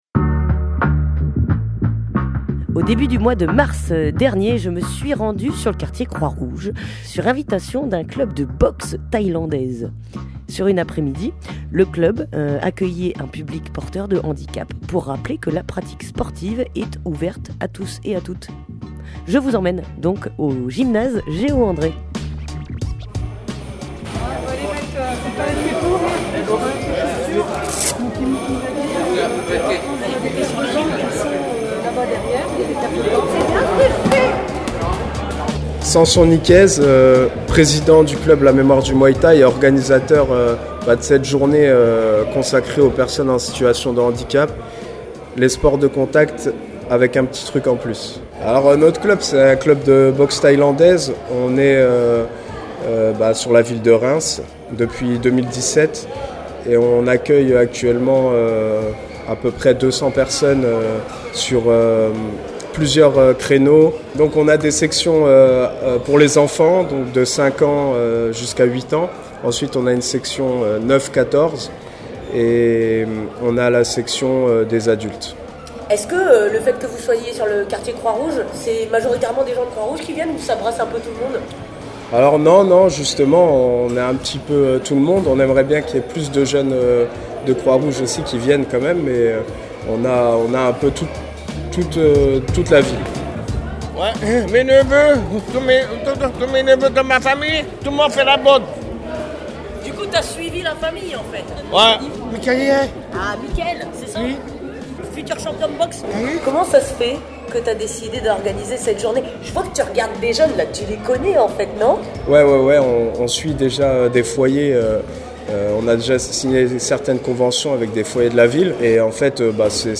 Reportage au complexe Géo André